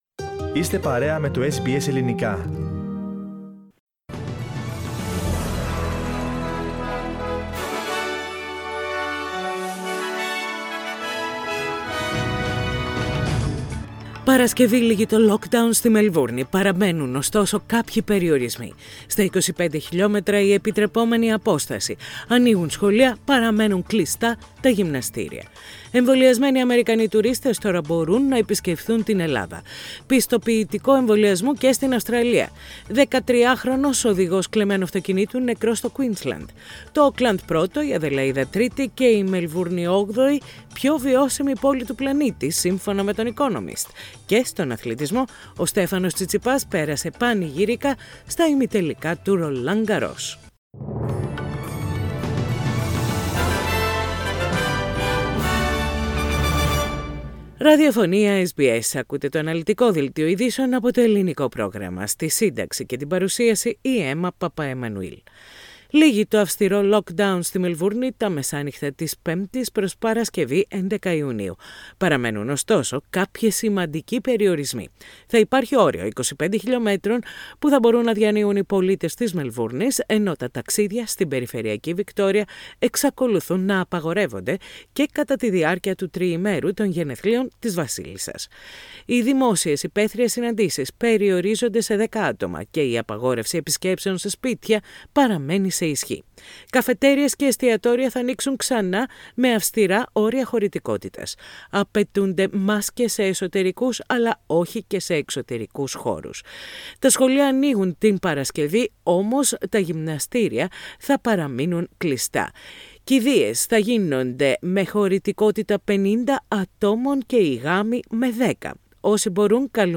Ειδήσεις στα Ελληνικά - Τετάρτη 9.6.21
Οι κυριότερες ειδήσεις της ημέρας από το Ελληνικό πρόγραμμα της ραδιοφωνίας SBS.